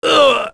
Roi-Vox_Damage_01.wav